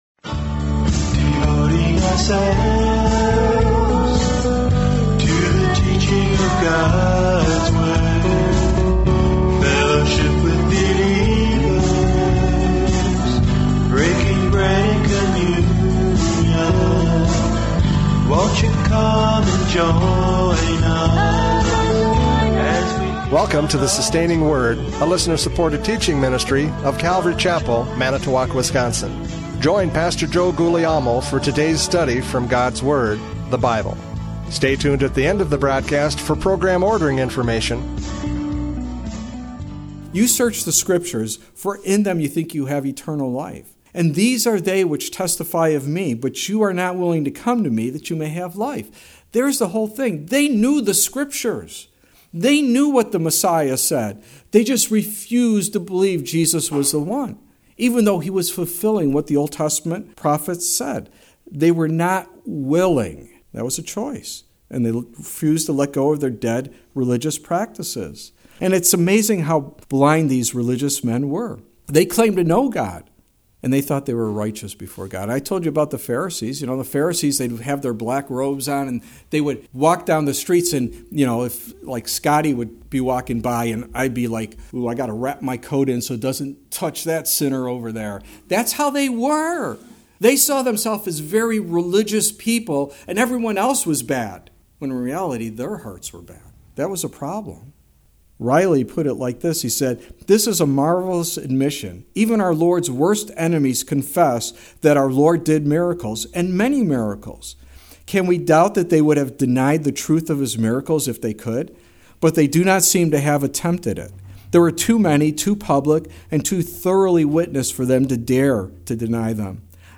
John 11:45-57 Service Type: Radio Programs « John 11:45-57 Blind Leaders!